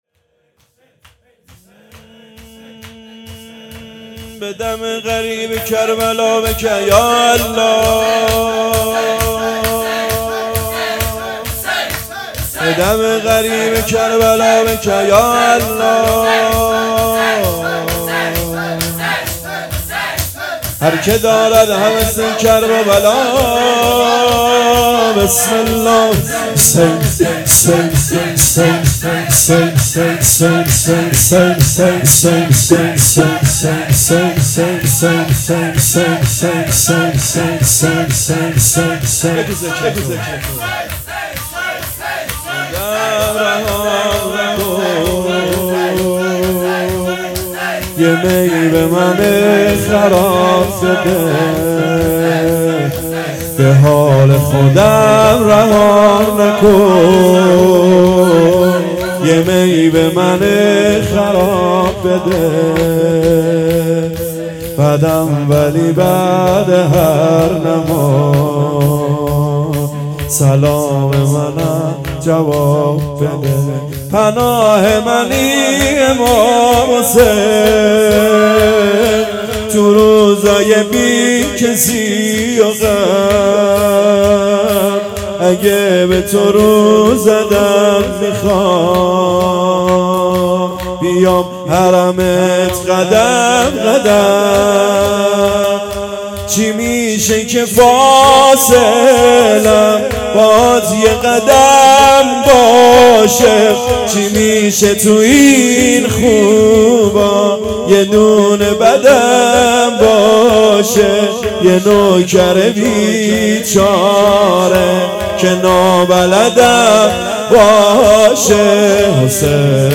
به حال خودم رهایم نکن _ شور
اقامه عزای شهادت حضرت زهرا سلام الله علیها _ دهه اول فاطمیه